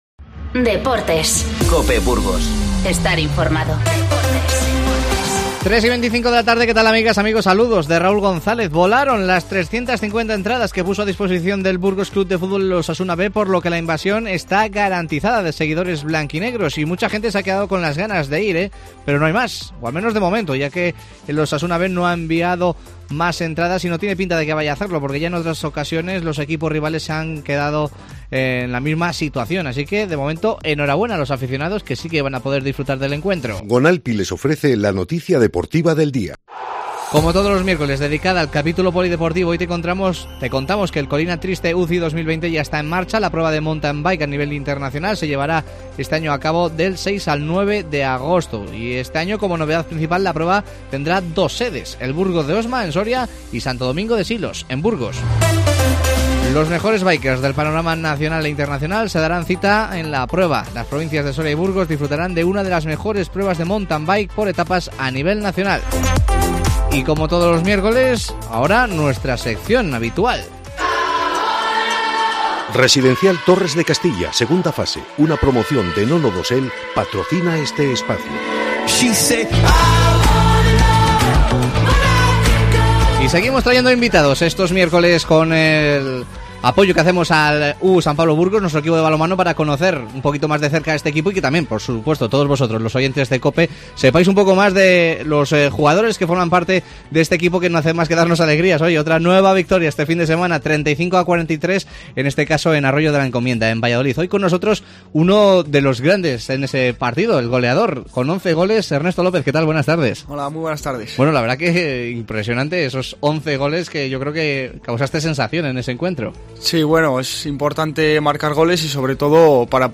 04:00 H | 23 DIC 2025 | BOLETÍN